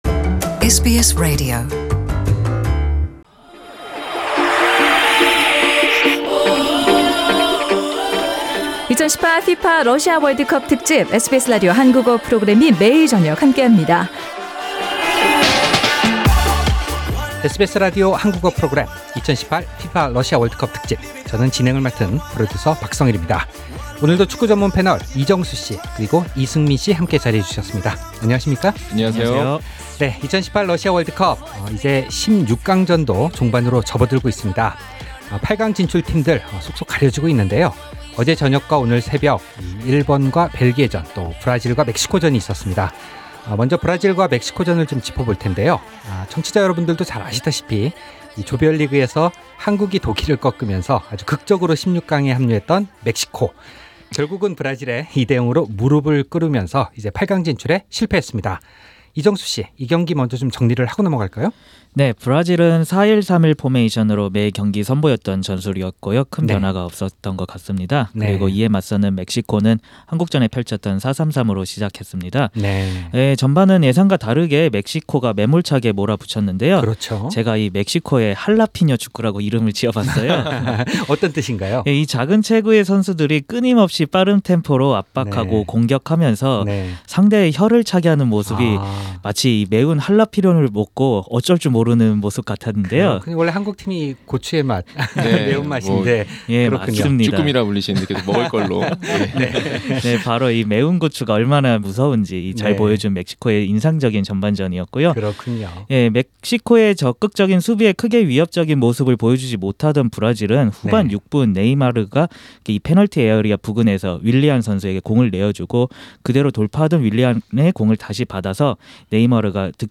The World Cup panel of two in Melbourne